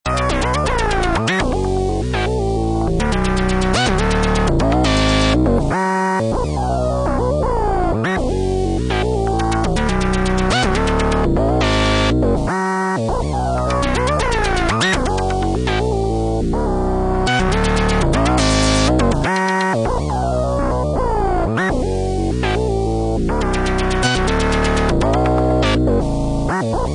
more images of the new analogue sequencer inSEQt from Anyware, which worked fine yesterday on it’s test run..
FIRST AUDIO (5mins cut up .. so nothing special, but working..)